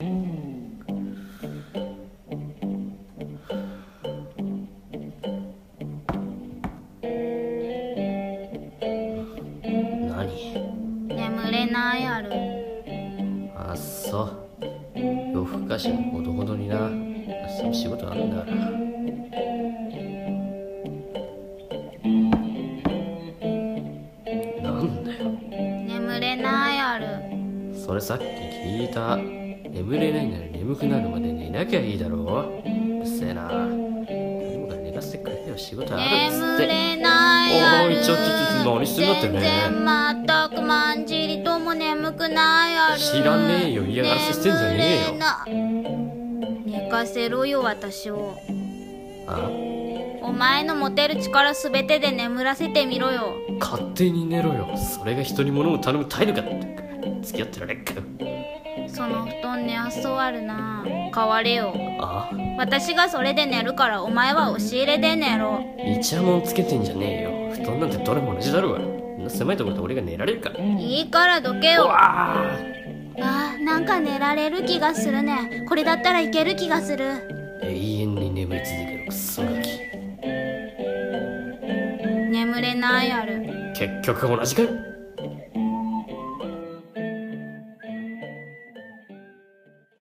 【銀魂·声劇】眠れないアル